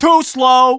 The Scout on immobility
Scout_invinciblechgunderfire02.wav